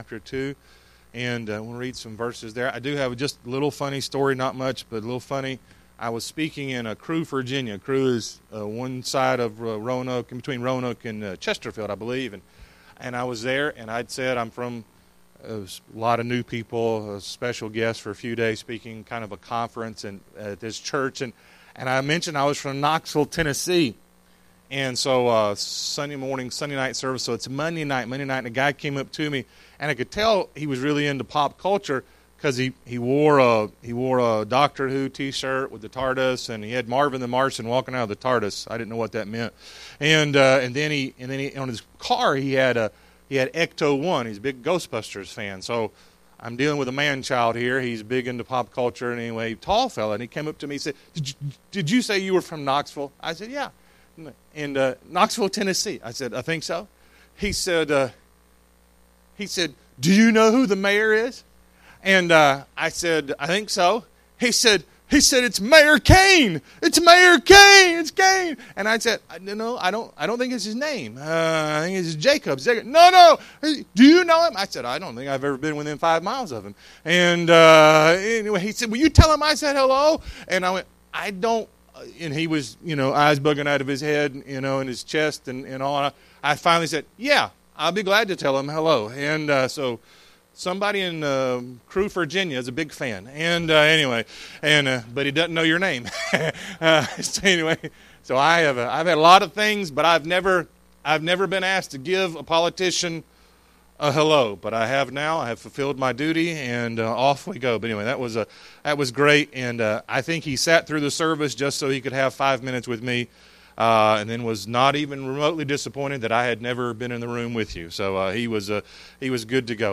1 Corinthians 2:6-16 Service Type: Sunday AM It is the Greatest Thing in the World! 1 Corinthians 2:6-16 Worldly Wisdom Says ...